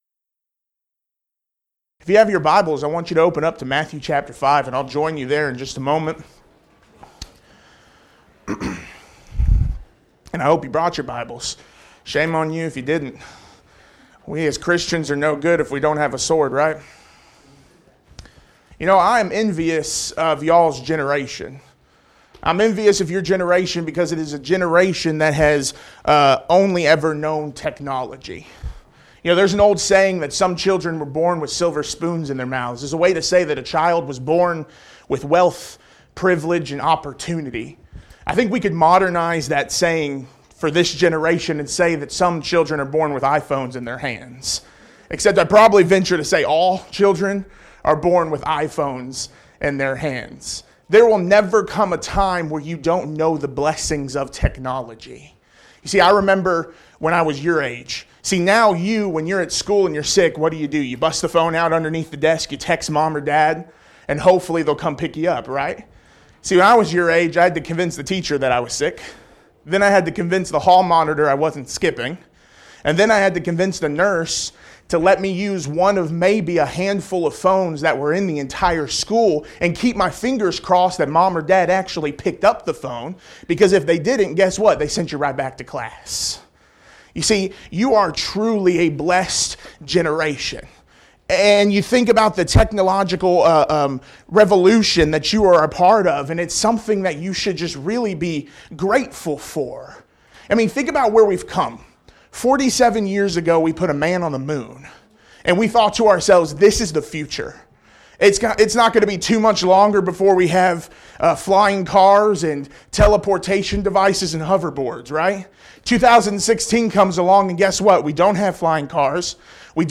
Event: 2016 Focal Point Theme/Title: Preacher's Workshop